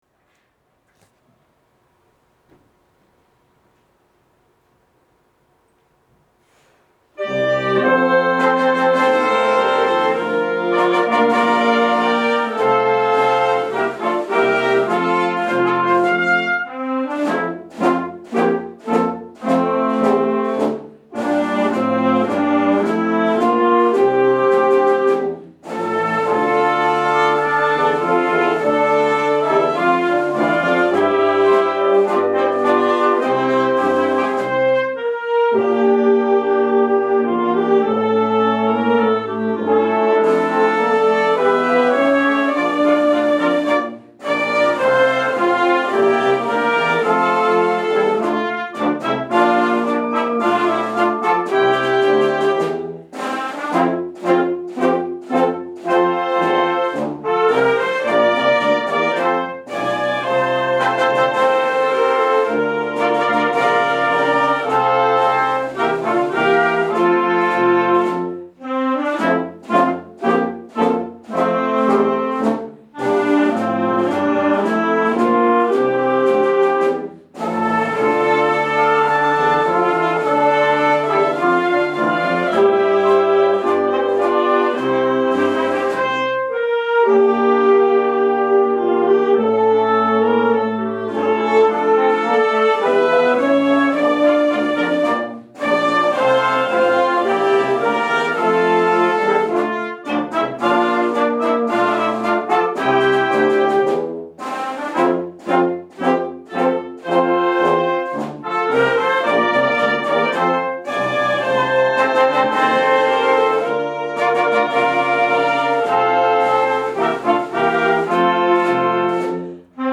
Kosaishs-schoolsong.mp3